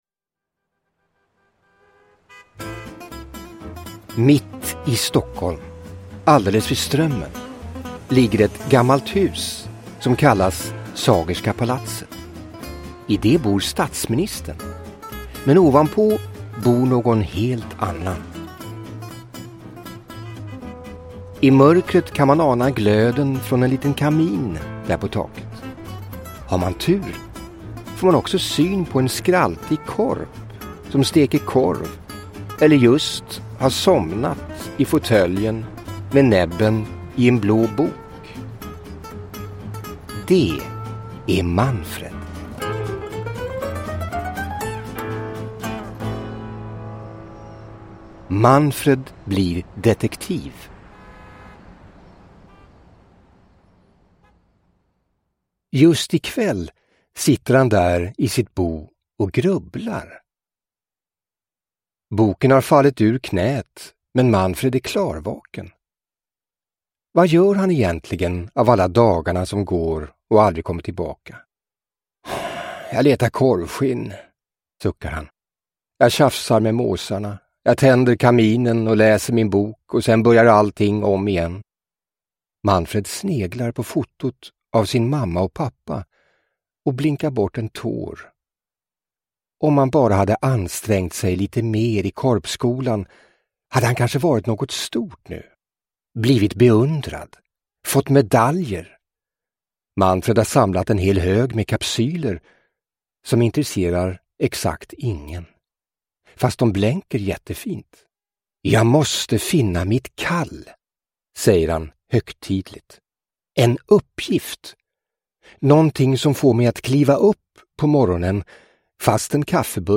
Manfred blir detektiv – Ljudbok – Laddas ner